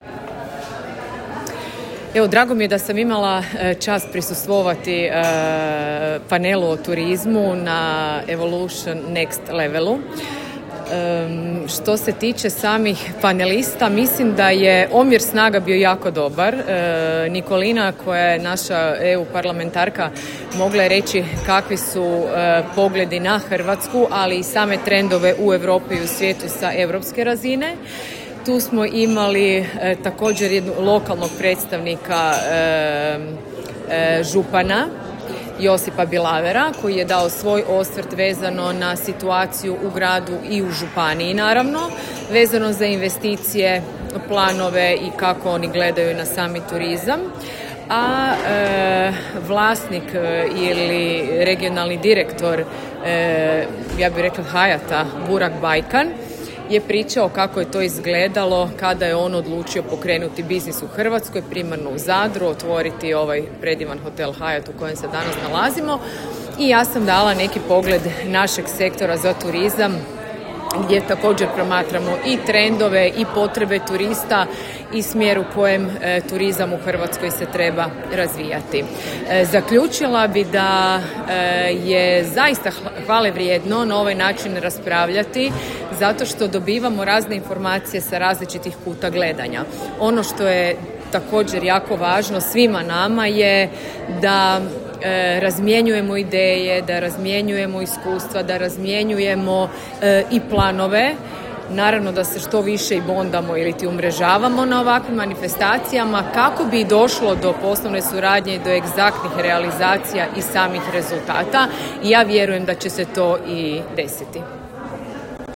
Upravo je to pitanje otvorio prvi panel konferencije Evolution Next Level u Zadru, simbolično nazvan „Savršena temperatura mora?“, koji je okupio predstavnike europskih institucija, gospodarstva, investitora i regionalne politike.